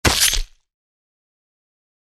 "Quick Saber Cut" From Mixkit